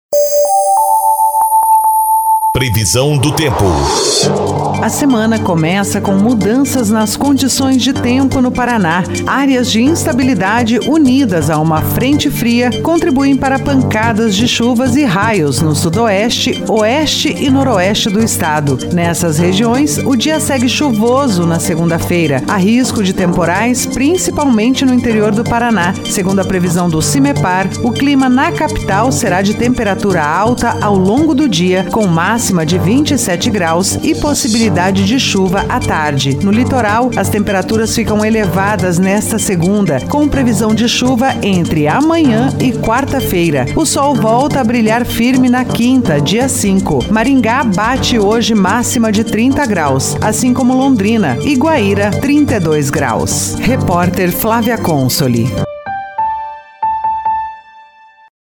Previsão do tempo 02/01/23